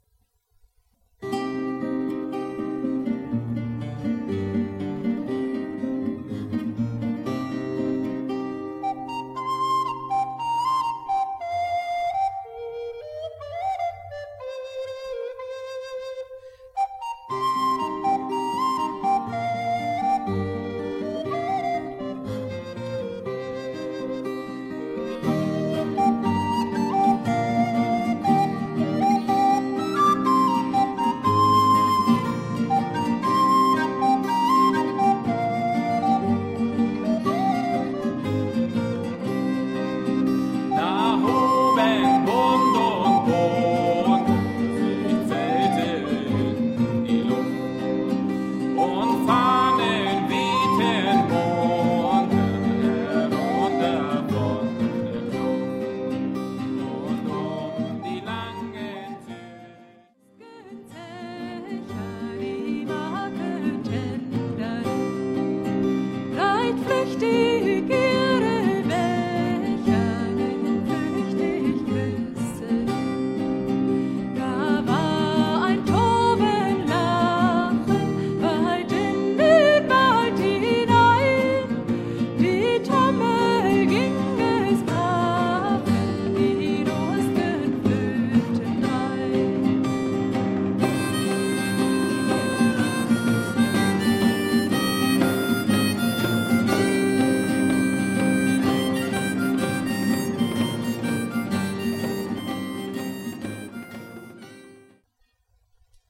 musik: traditionell irisch
unsere cd ist ein mitschnitt unserer musiktreffen
in der mittelalterlichen st. servatius-kirche zu selent